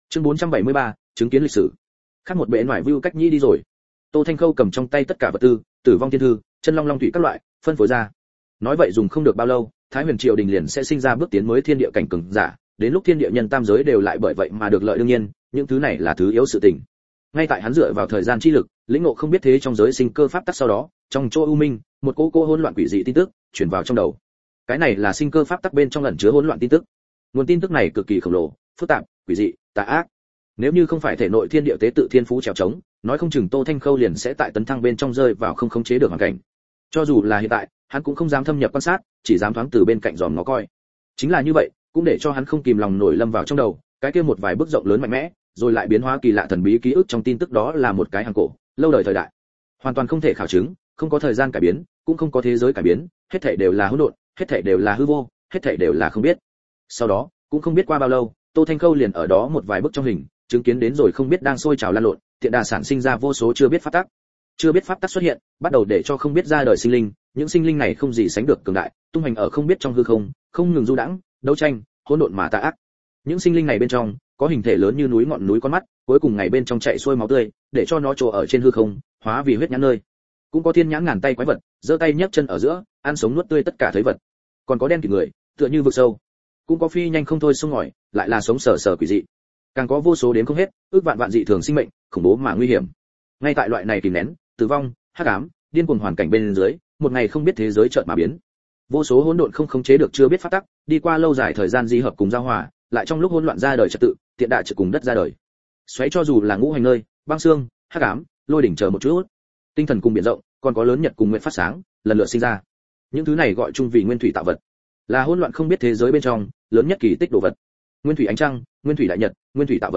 Ngả Bài Rồi , Ta Chính Là Một Con Rồng Audio - Nghe đọc Truyện Audio Online Hay Trên TH AUDIO TRUYỆN FULL